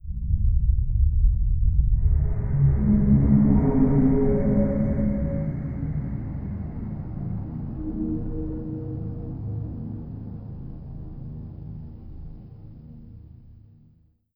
Samsung Galaxy S110 Startup.wav